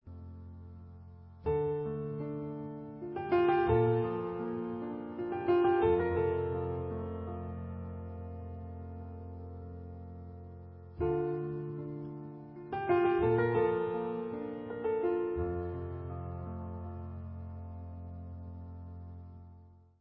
Klasika